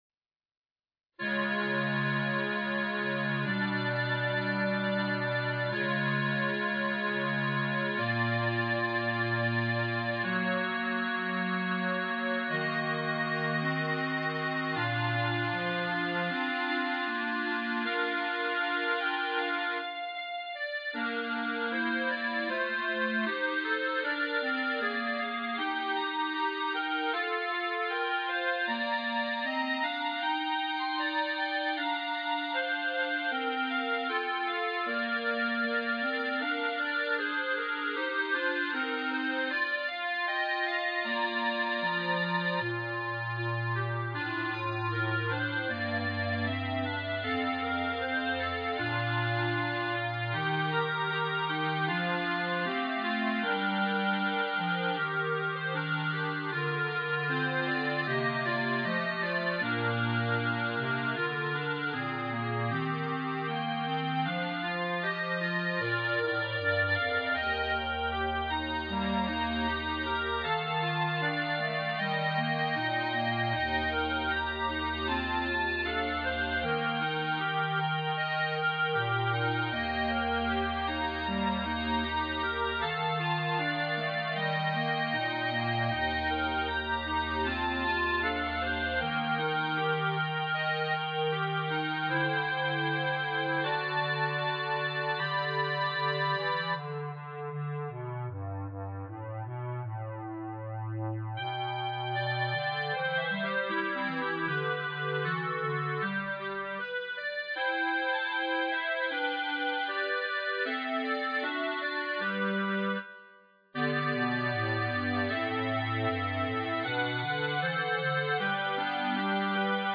B♭ Clarinet 1 B♭ Clarinet 2 B♭ Clarinet 3 Bass Clarinet
单簧管四重奏
童谣
优美且富有情感的旋律编配得易于演奏。